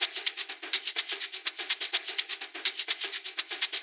Live Percussion A 13.wav